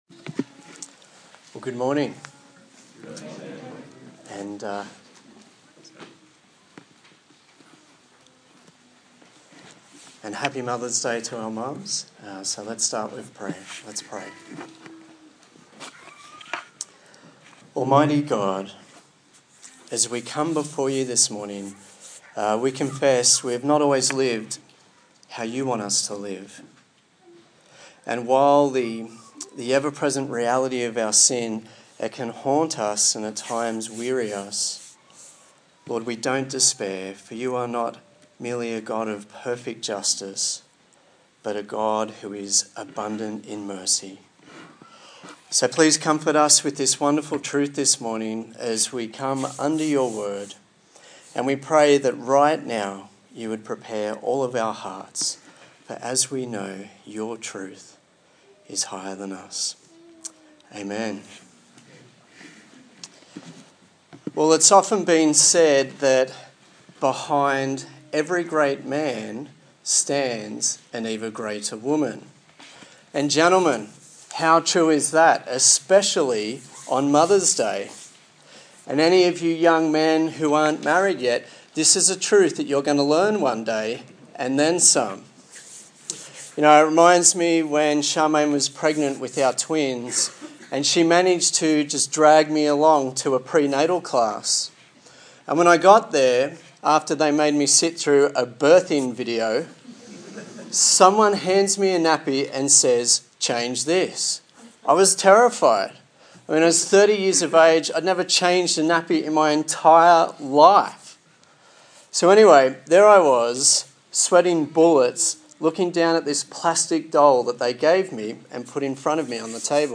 Colossians Passage: Colossians 1:24-29 Service Type: Sunday Morning